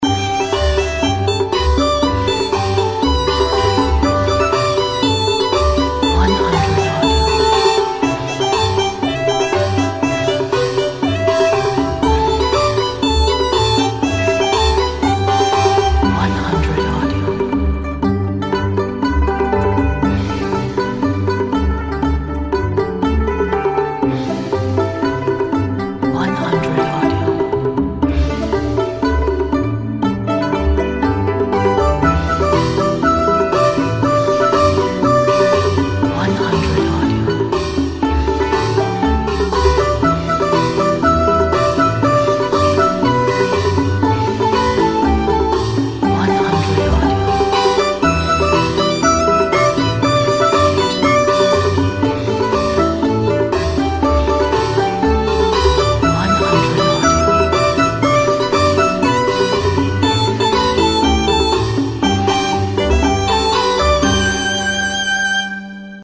中国风 活泼 欢快
炫酷 国潮 中国风 走秀 RAP trap
过年 春节 喜庆（3版本）
城市 嘻哈 放克（5版本）
亚洲风格 Trap（4版本）
中国 嘻哈